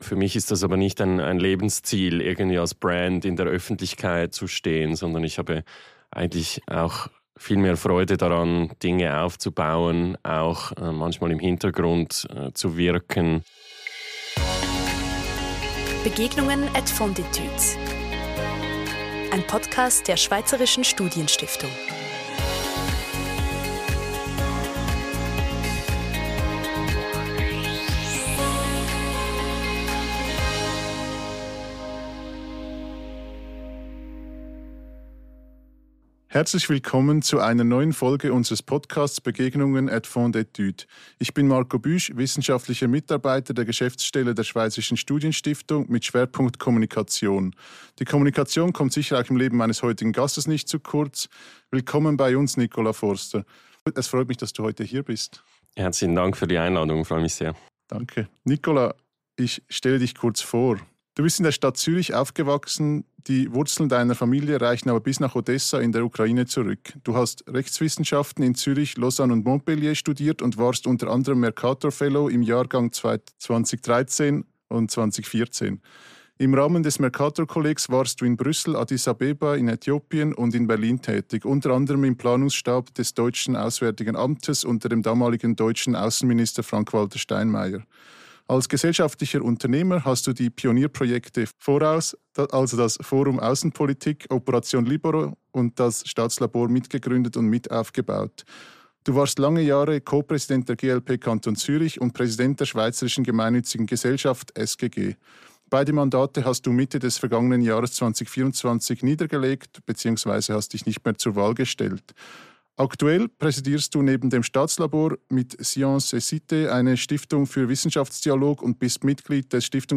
Ein Gespräch über gesellschaftliches Unternehmertum, Erfolgsrezepte für den Aufbau von Organisationen, mögliche Formen der Demokratie und vom Scheitern und daraus lernen.